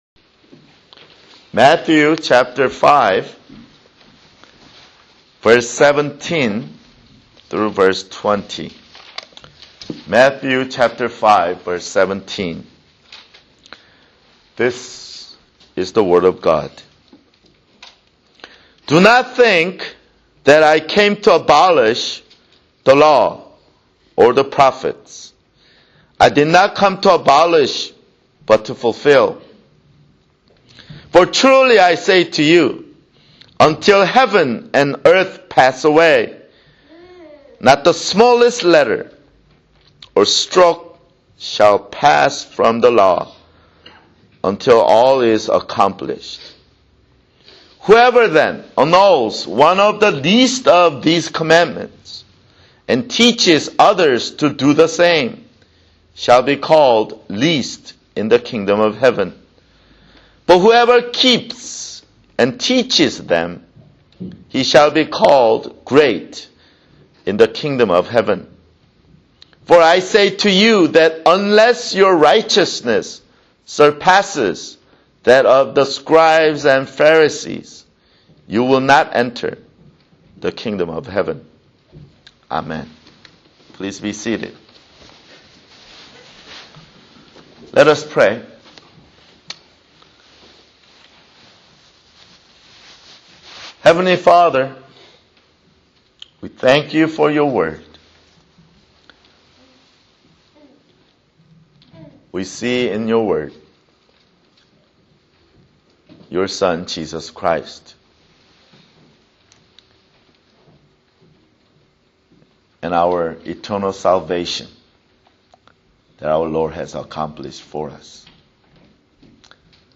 Sunday, April 15, 2007 [Sermon] Matthew (24) Matthew 5:17-20